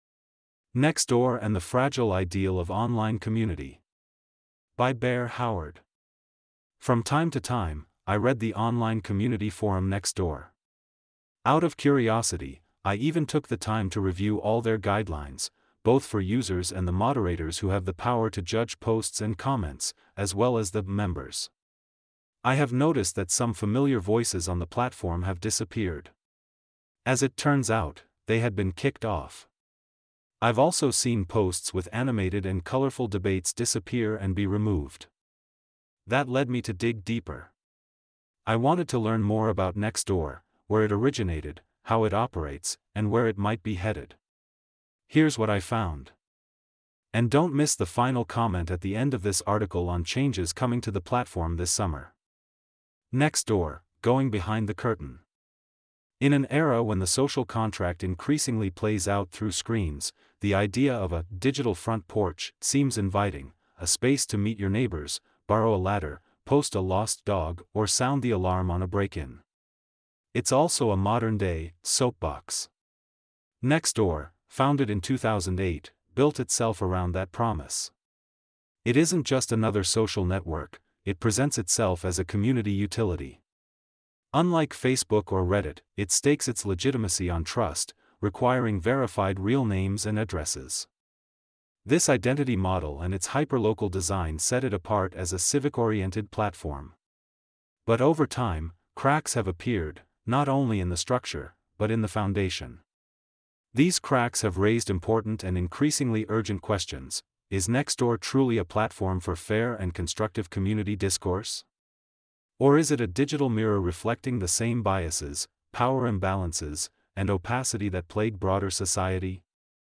Click the arrow to listen to this article narrated for you.